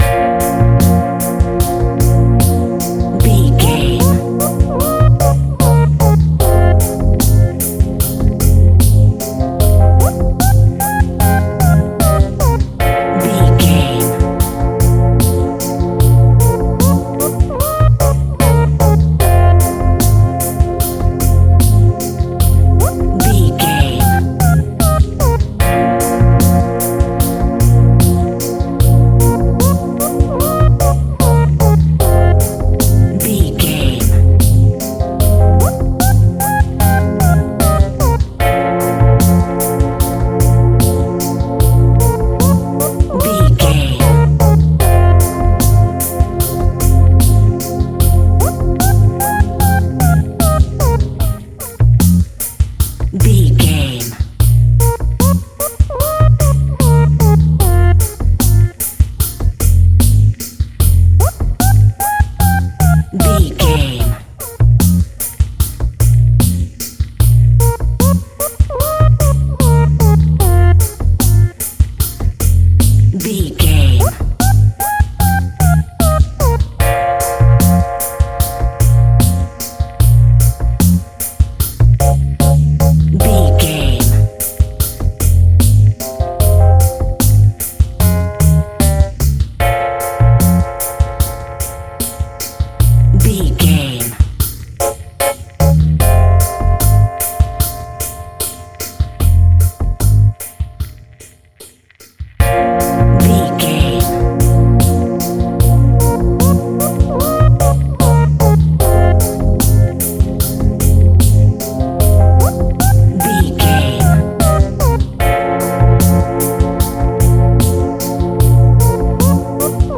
In-crescendo
Thriller
Aeolian/Minor
ominous
haunting
eerie
synths
Synth Pads
atmospheres